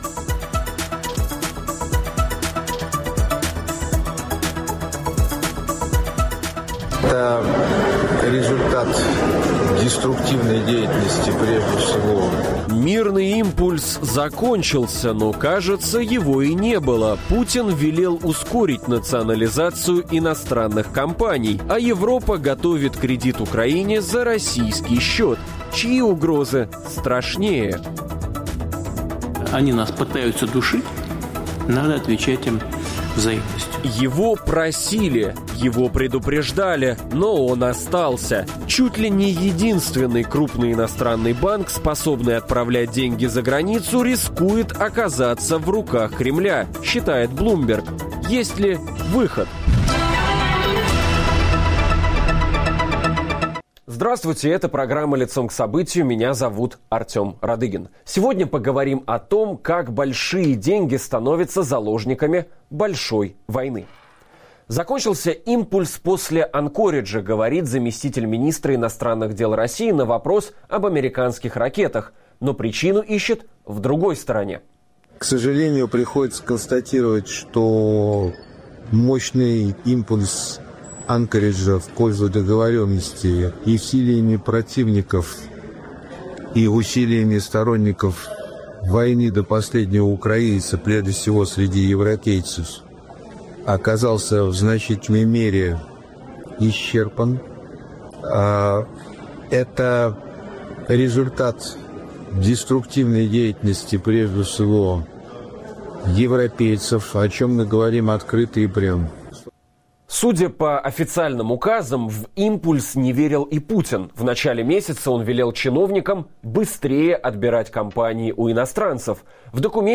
О "заложниках" Путина, о тех иностранных компаниях, которые могут попасть в руки Кремля, а также о спорах вокруг замороженных денег говорим в программе "Лицом к событию" с экономистом Игорем Липсицем.